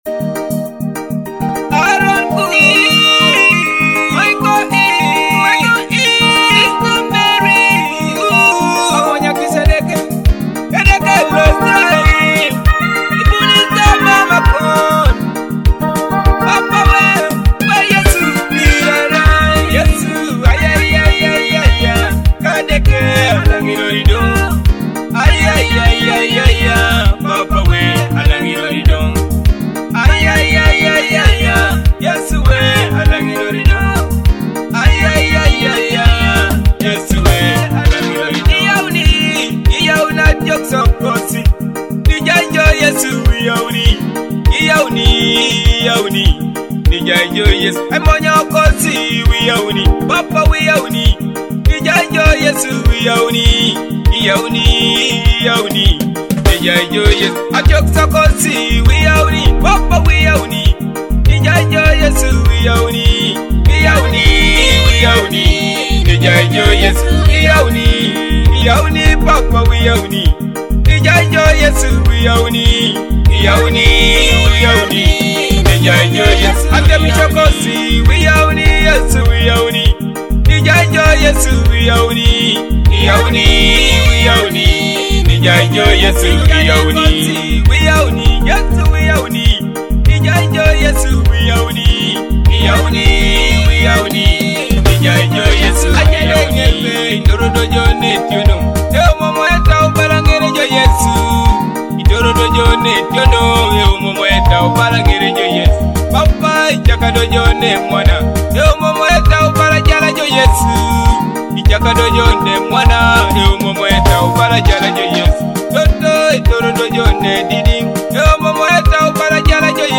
heartfelt worship